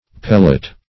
Pellet \Pel"let\, v. t.